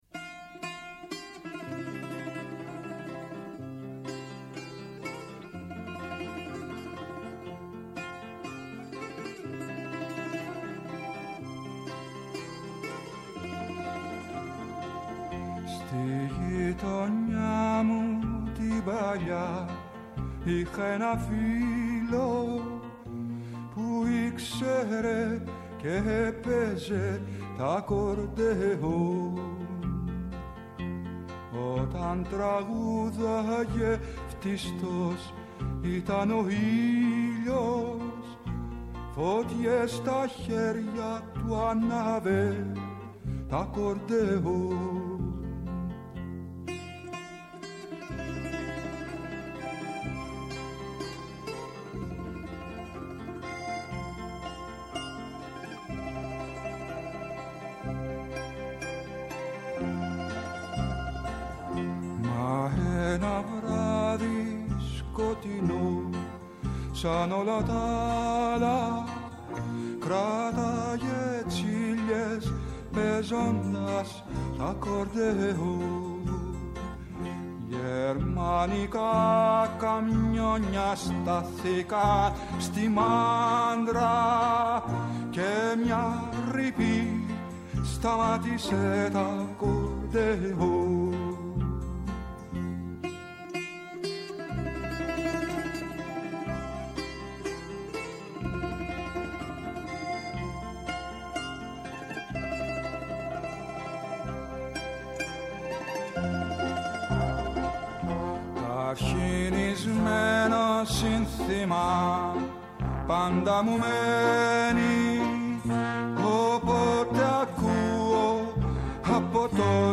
-ο Πάνος Τσακλόγλου, υφυπουργός Εργασίας, αρμόδιος για θέματα Κοινωνικής Ασφάλισης